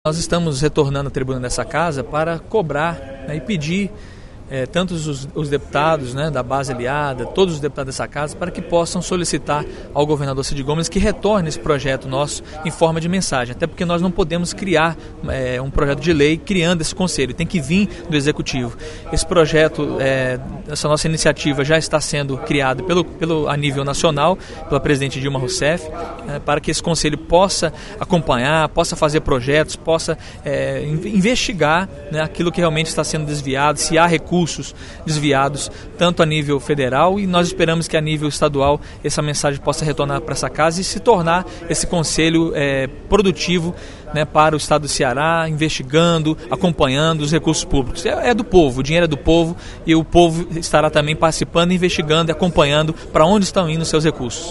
Na sessão plenária desta terça-feira (20/03) da Assembleia Legislativa, o deputado Ronaldo Martins (PRB) destacou o projeto de indicação n° 227/11, de sua autoria, que cria o Conselho de Transparência Pública e Combate à Corrupção.